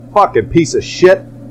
Play, download and share Ave Piece of Shit2 original sound button!!!!
avelessshitmorebass.mp3